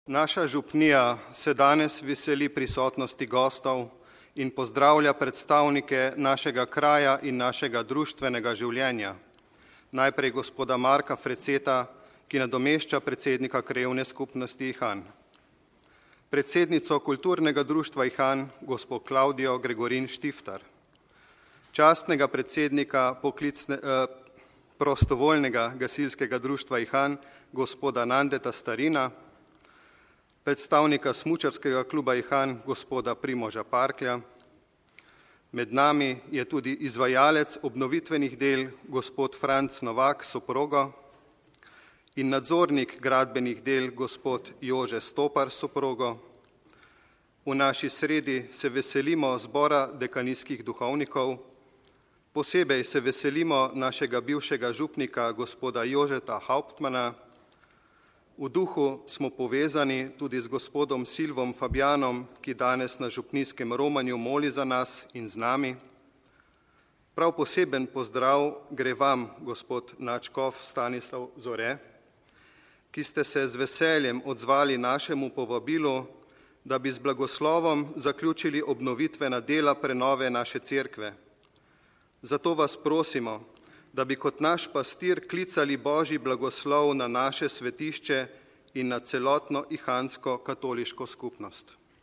IHAN (sobota, 27. avgust 2016, RV) – Ljubljanski nadškof metropolit msgr. Stanislav Zore je ob priložnosti blagoslovitve obnovljene zunanjščine cerkve daroval sveto mašo v župnijski cerkvi sv. Jurija v Ihanu.